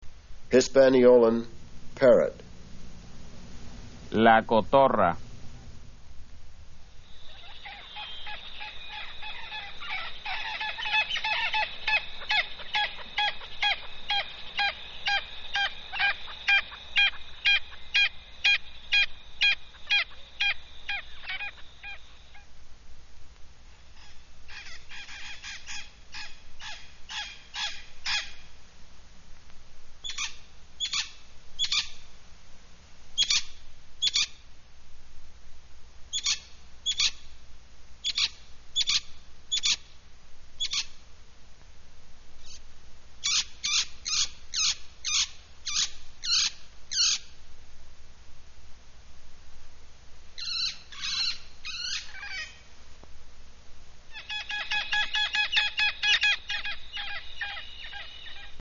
Bird Sounds from Hispaniola
Hisp-Parrot
Hisp-Parrot-2.mp3